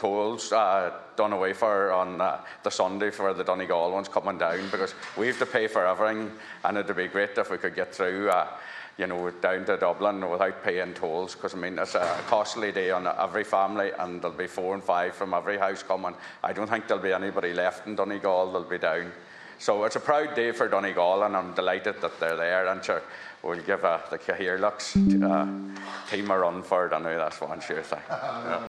Senator Manus Boyle says it will be a “costly day” for families travelling down: